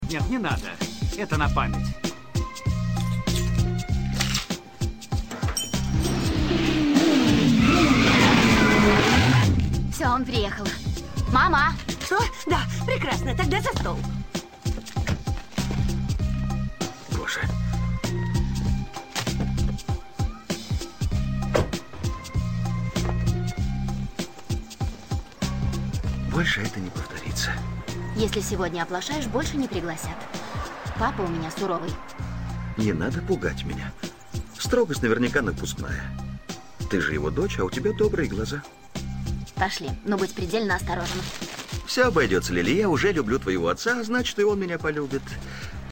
• Category: Music from the Movies